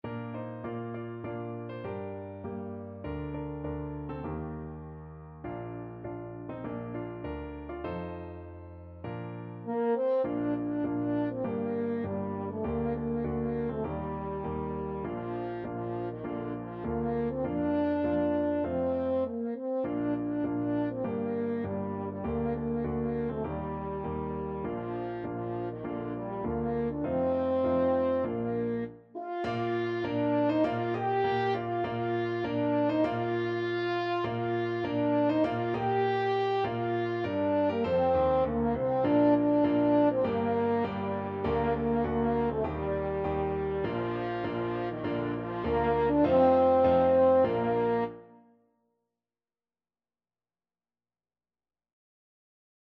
Free Sheet music for French Horn
Bb major (Sounding Pitch) F major (French Horn in F) (View more Bb major Music for French Horn )
4/4 (View more 4/4 Music)
Moderato
French Horn  (View more Easy French Horn Music)
Traditional (View more Traditional French Horn Music)